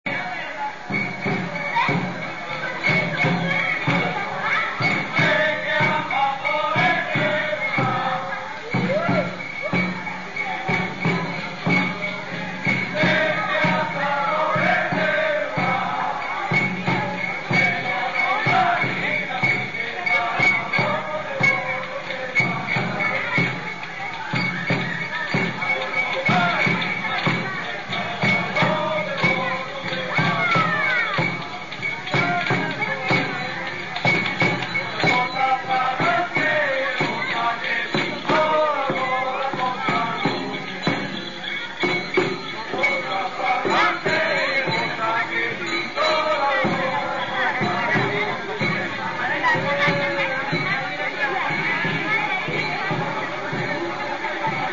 El "tan tán tan tararán tan" de la tambora, acompasado, sonoro y enérgico les llega al alma a los cambileños.
Hay muchas estrofas, el poeta no escatimó, pero la gente repite sólo unas pocas y éstas cantadas con desorden y un poco atropelladamente.
También algunos echan sus traguillos, los que van toda la noche acompañando con sus instrumentos de cuerda.